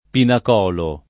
pinacolo [ pinak 0 lo ]